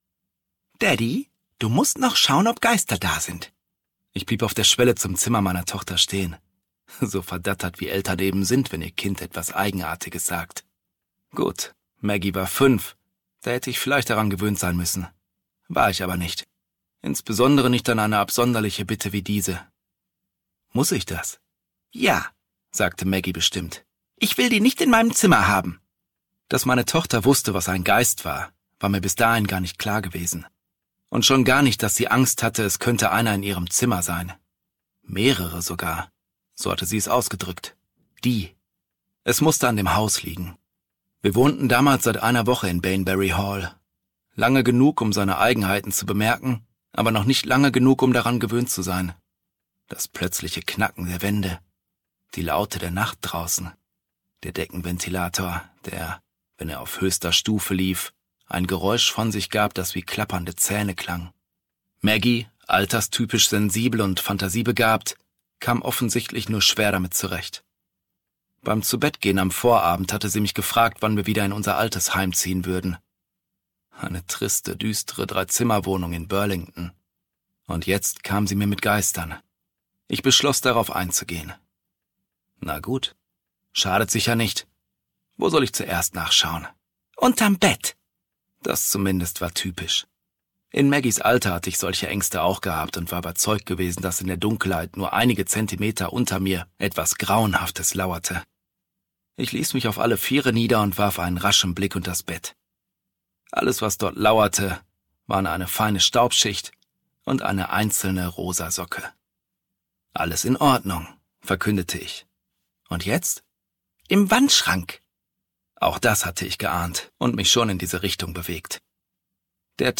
2022 | Ungekürzte Lesung